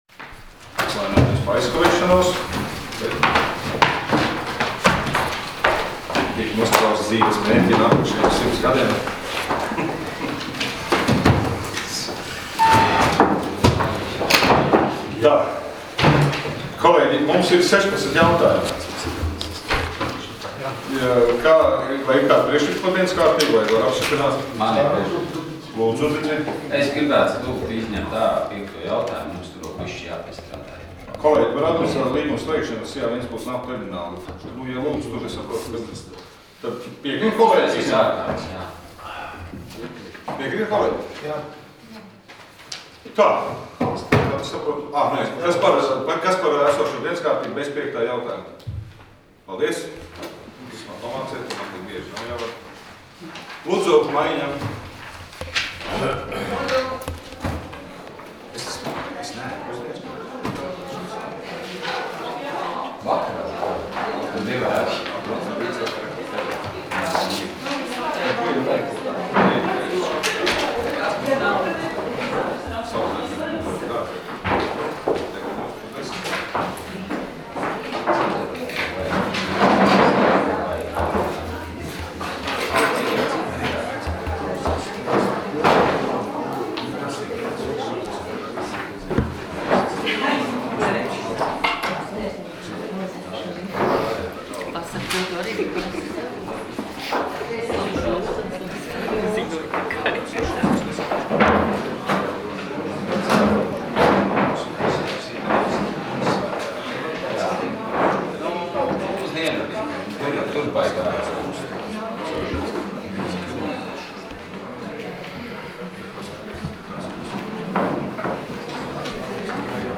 Domes sēdes 10.02.2017. audioieraksts